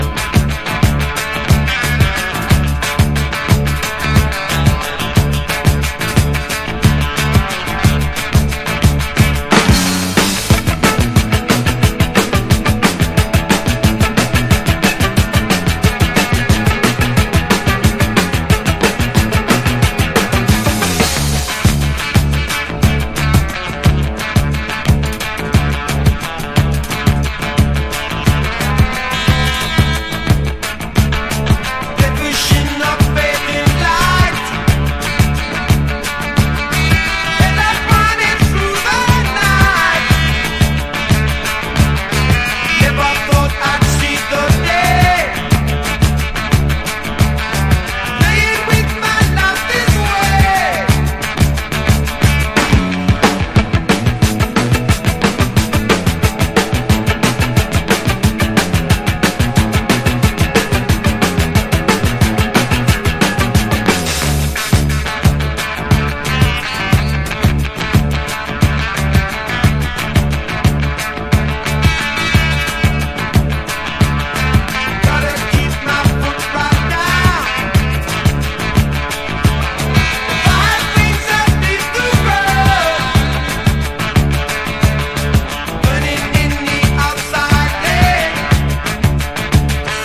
前作から更に音を削ぎ落し3ピースの最小編成で〈ホワイトレゲエ〉と呼ばれた独自のグルーヴを生み出してます。